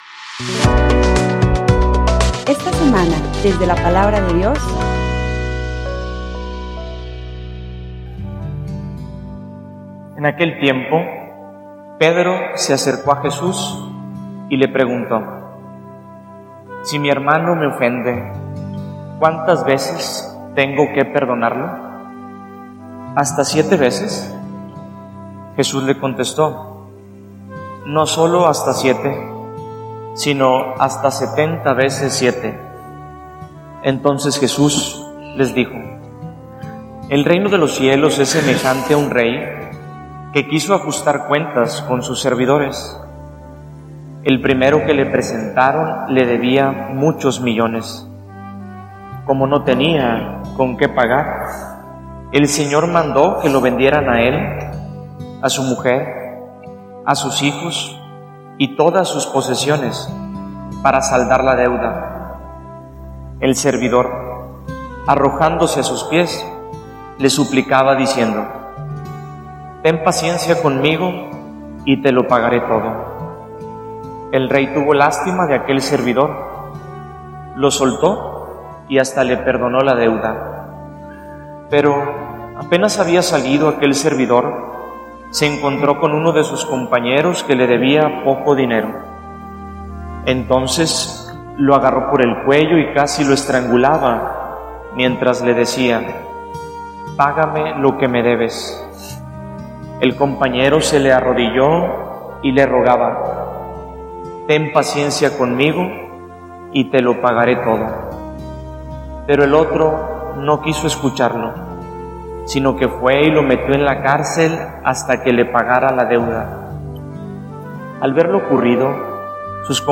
homilia_Por_que_somos_una_gran_comunidad.mp3